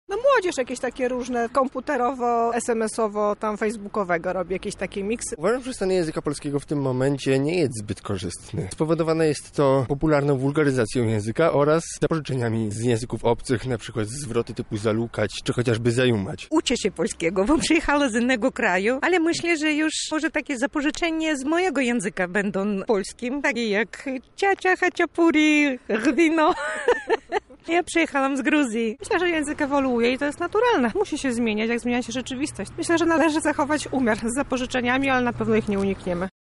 Z tej okazji zapytaliśmy mieszkańców Lublina czy według nich nasz język traci na wartości.